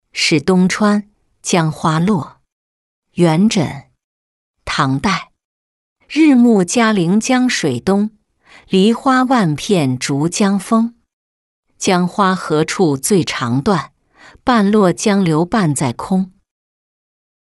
使东川·江花落-音频朗读